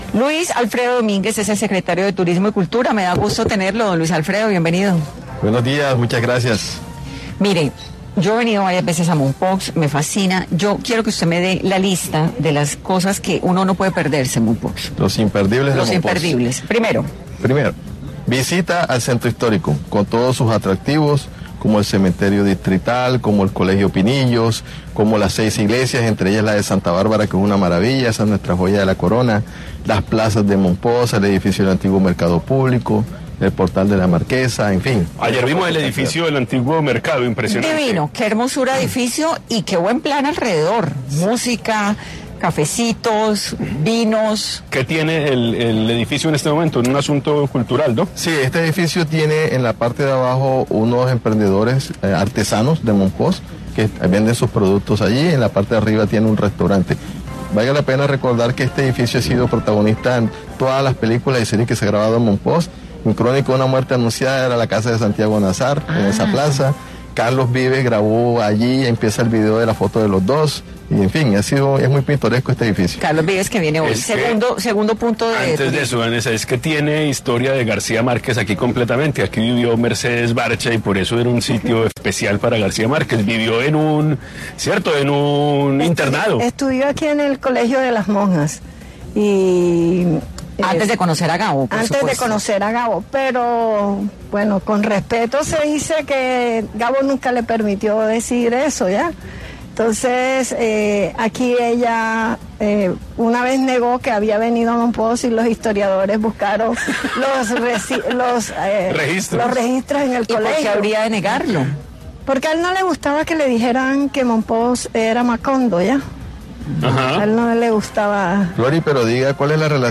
Caracol Radio está en Mompox, es por esto que en el programa de 10AM Hoy por Hoy estuvo Luis Alfredo Domínguez, secretario de Turismo y Cultura de Mompox, para hablar sobre la décima edición del Festijazz 2024, que irá hasta el 15 de septiembre.